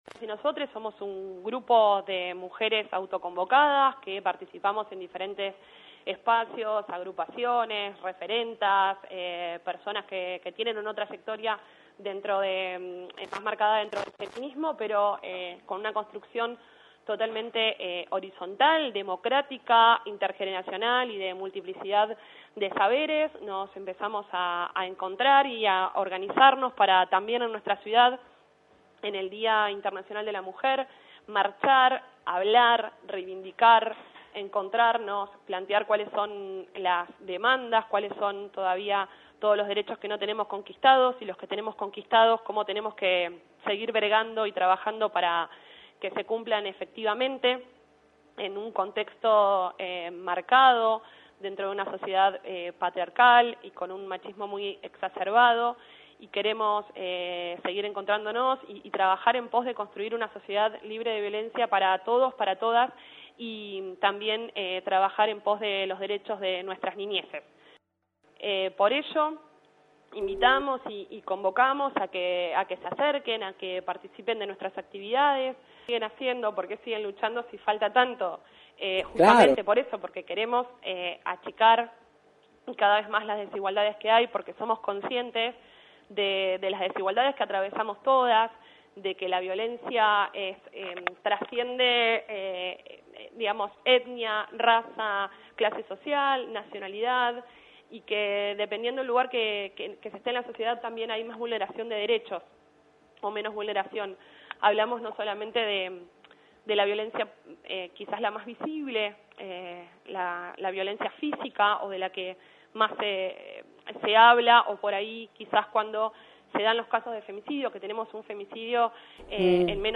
La concejal y una de las referentes del Grupo «Mujeres Autoconvocadas Las Flores», se refirió este miércoles en la 91.5 a la conmemoración del 8M que incluirá diversas actividades en todo el país y en particular en nuestra ciudad.